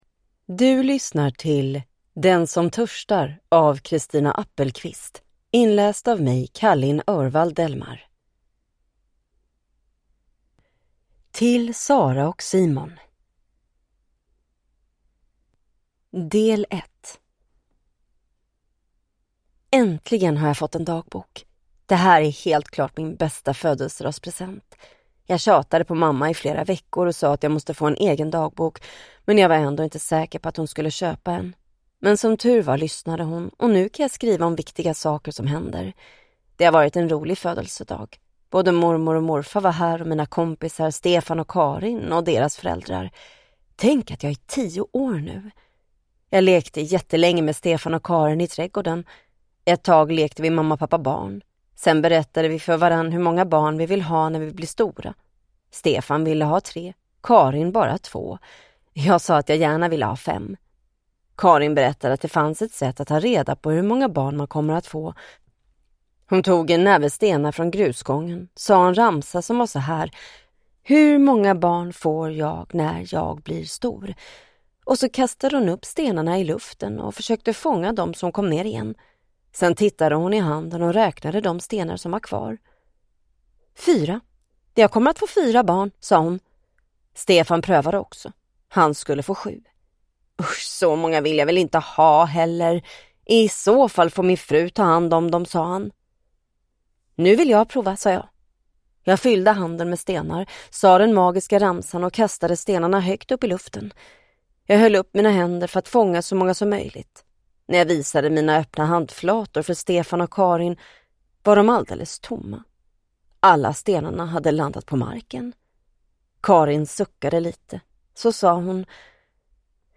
Ljudbok
Nyinspelad ljudbok med förbättrat ljud och populär inläsare!